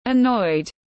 Khó chịu tiếng anh gọi là annoyed, phiên âm tiếng anh đọc là /əˈnɔɪd/
Annoyed /əˈnɔɪd/